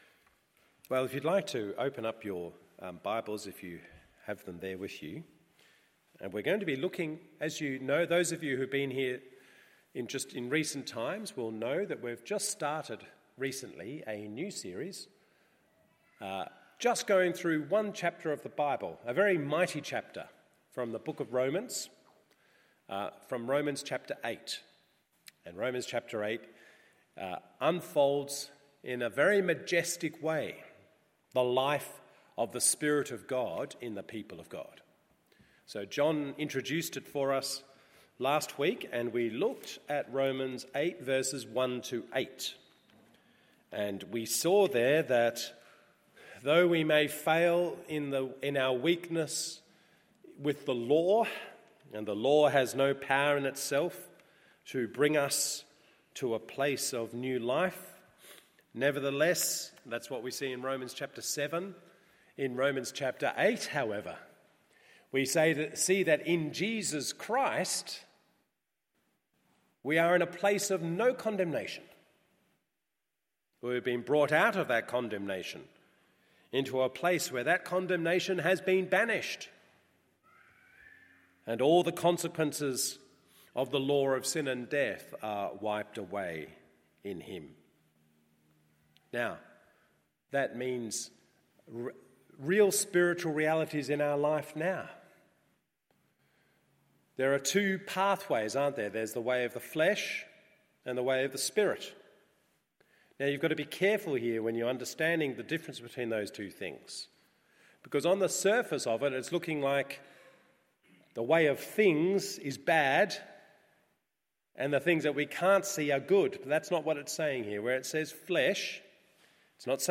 MORNING SERVICE Romans 8:1-17…